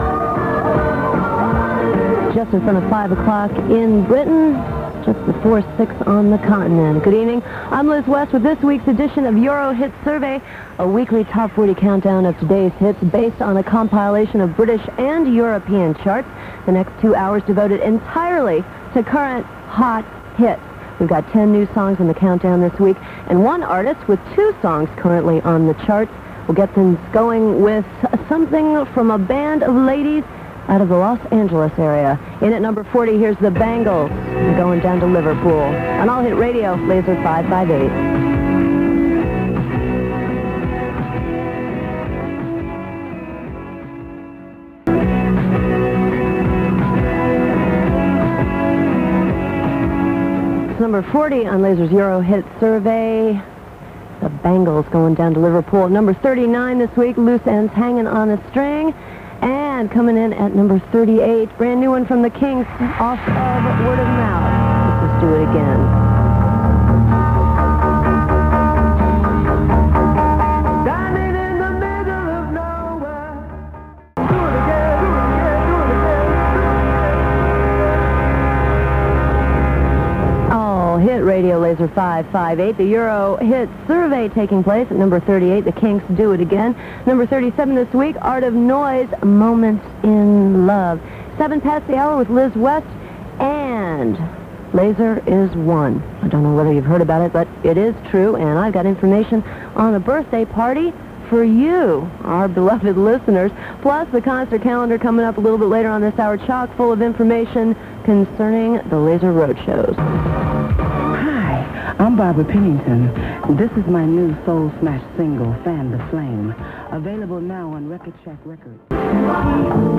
Top 40 chart show